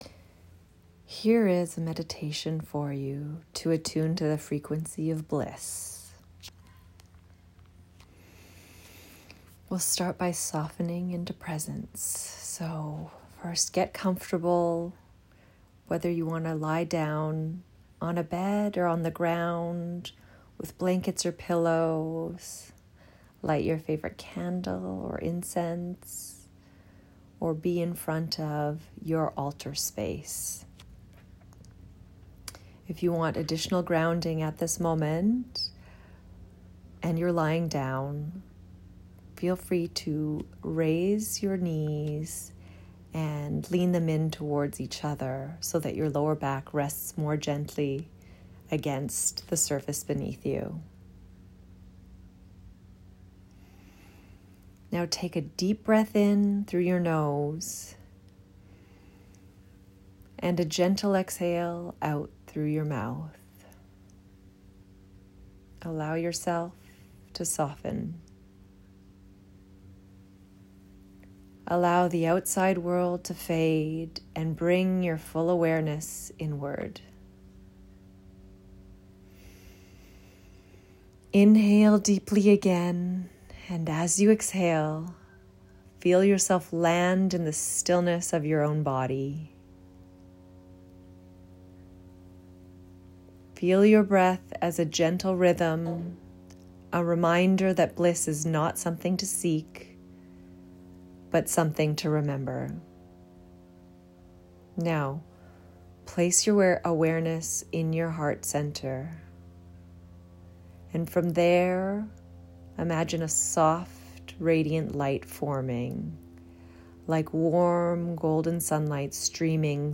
Guided Meditation: Attuning to the Frequency of Bliss